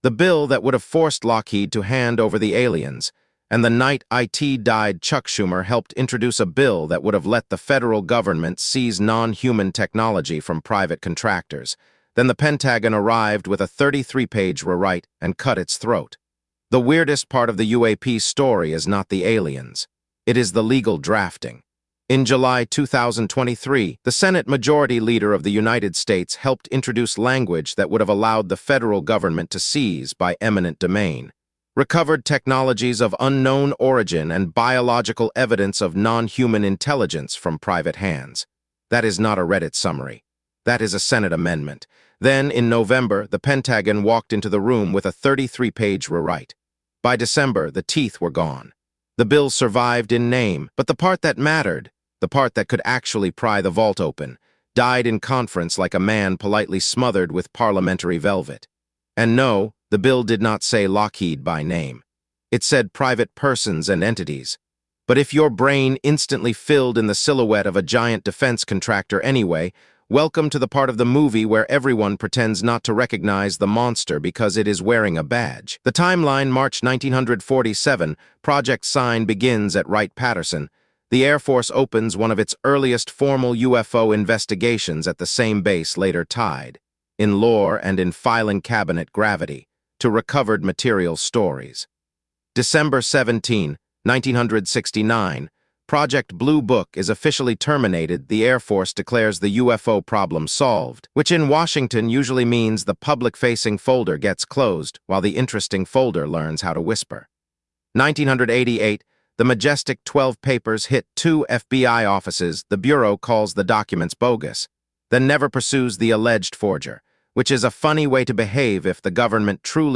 Read this article aloud